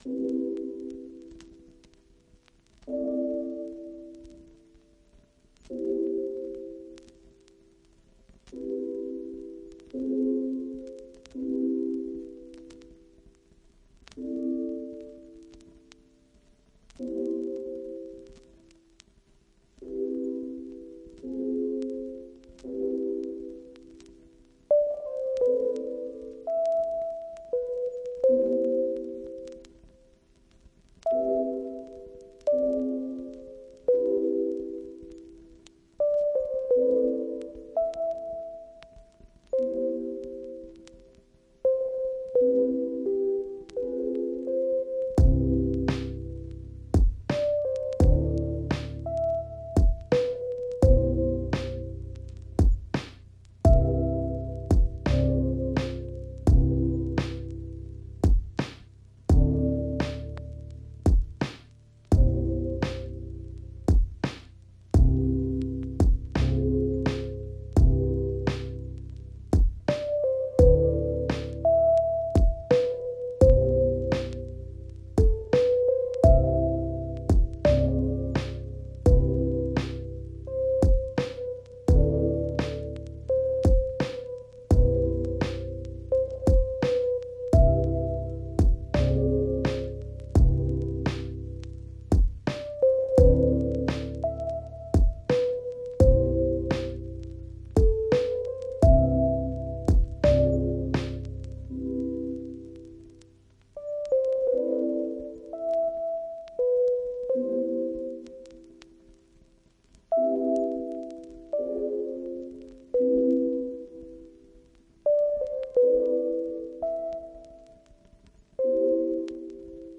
Lofi